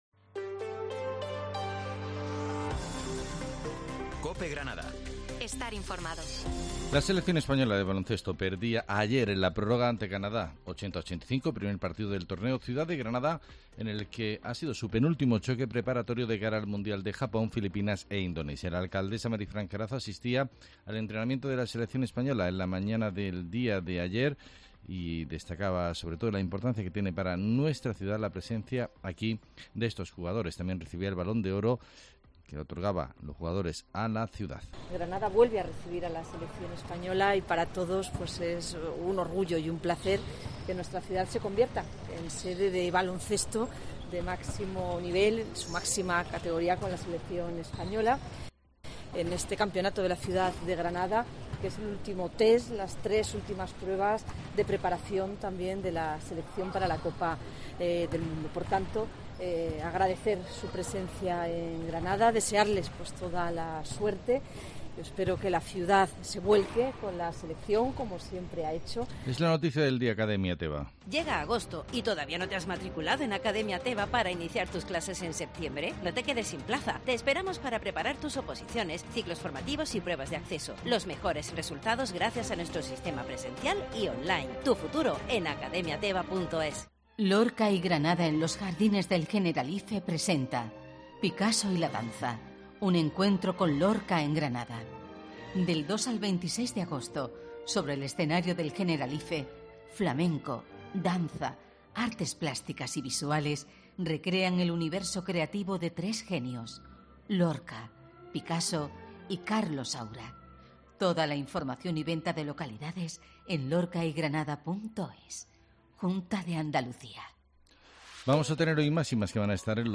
Herrera en Cope, Informativo de 18 de agosto